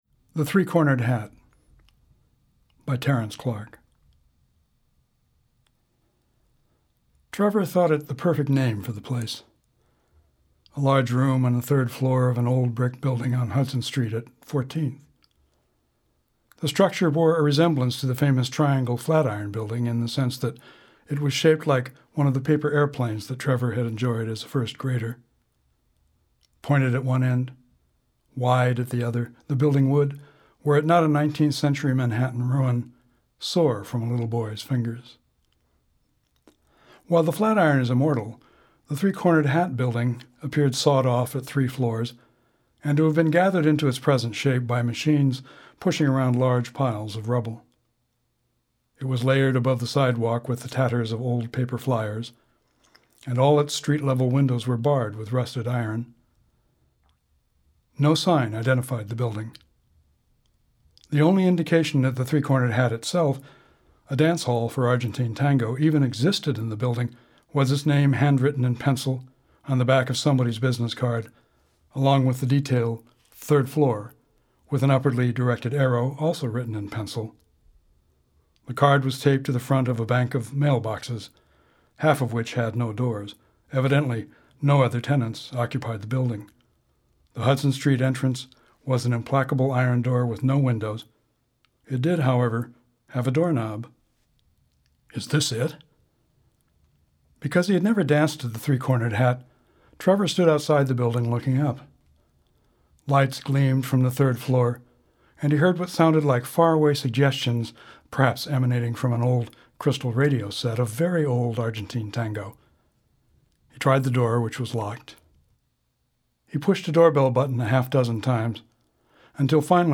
To hear my reading of it, CLICK HERE.